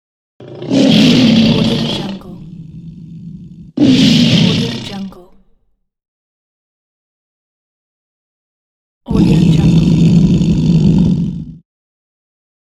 Lion Growling Bouton sonore
1. Jouer instantanément : Click the sound button above to play the Lion Growling sound immediately in your browser.